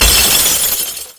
Звук треска и скрипа разбивающегося сердца